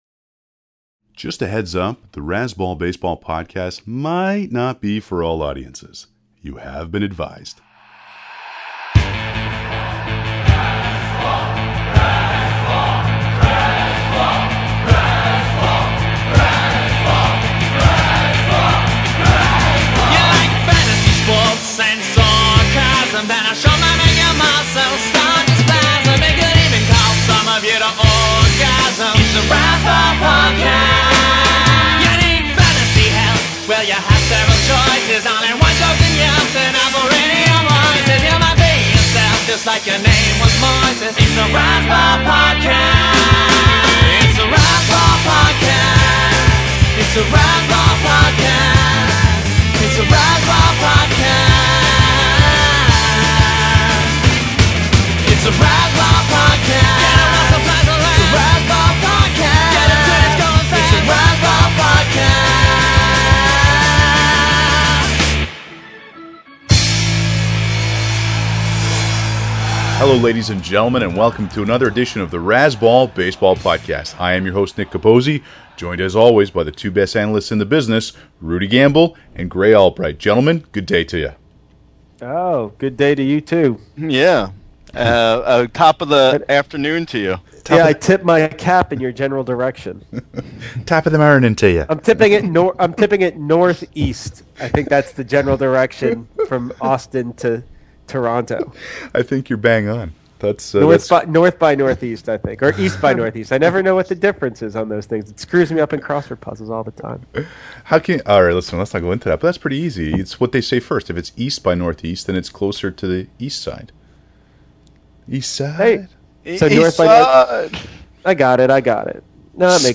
We have a Razzball Podcast Football theme song and it’s got a rap interlude.